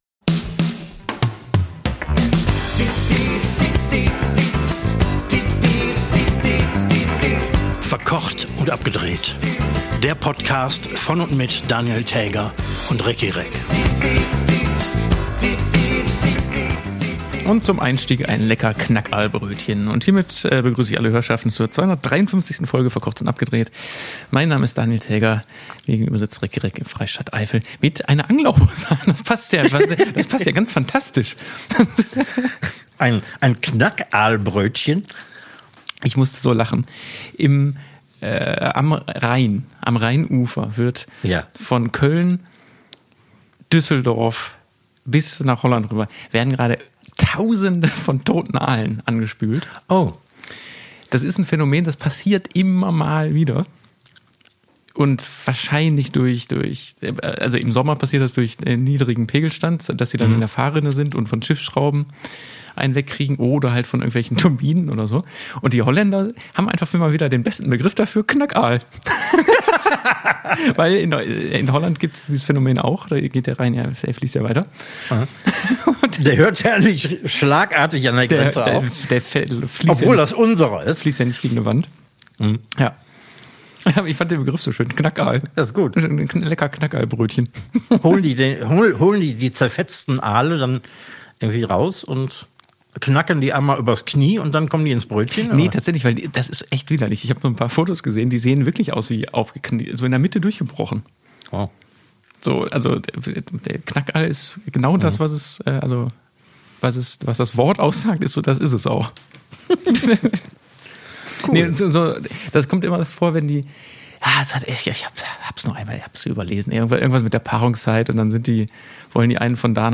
Koch und Fernsehmann präsentieren lauschige Musik, Tipps und einiges Unnützes für den privaten Hausgebrauch.